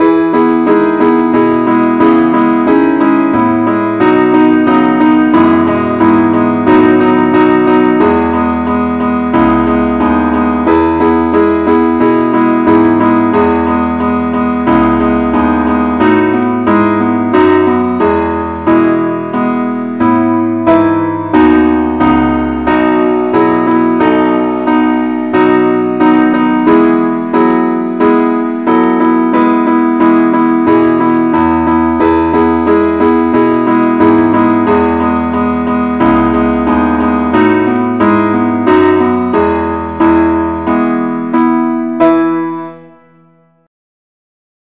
henley-keyboardonly-keye.mp3